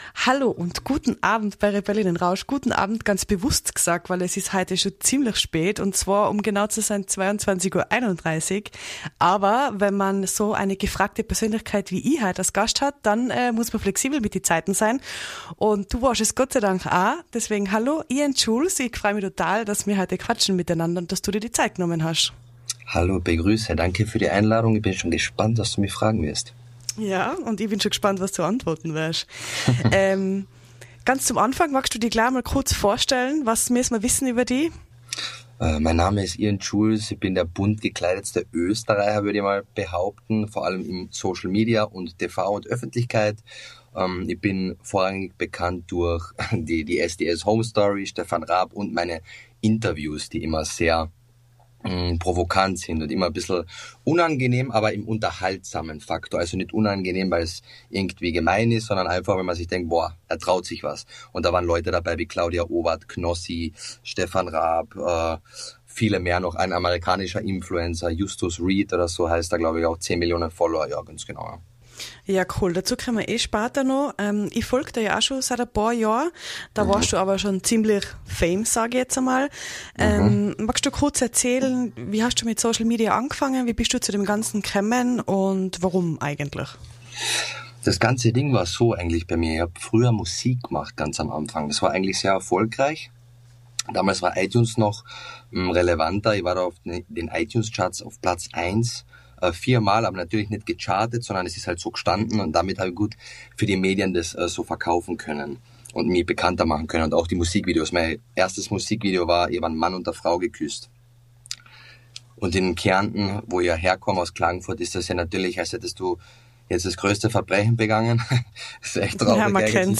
Eine ruhige, ehrliche und bunte Folge übers Bei-sich-Bleiben, egal wie laut die Welt wird.